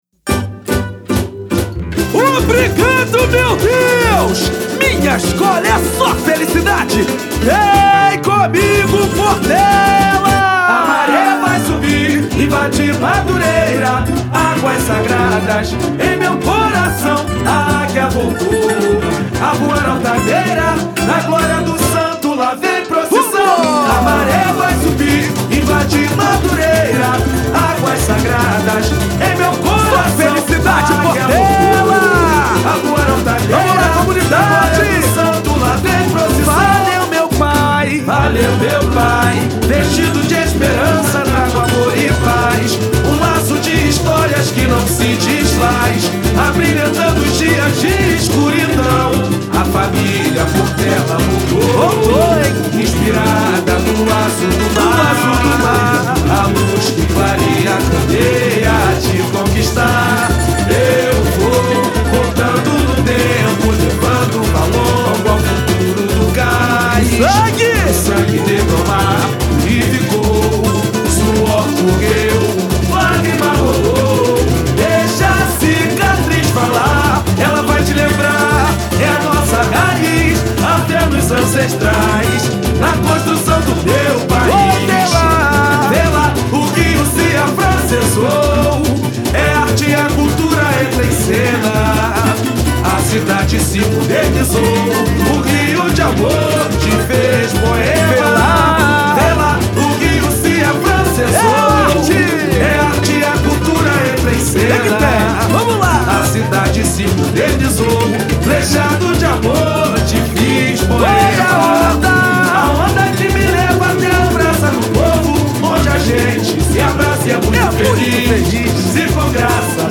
Dia que com meus parceiros inscrevo nosso samba da Portela, o nosso concorrente para o carnaval 2014.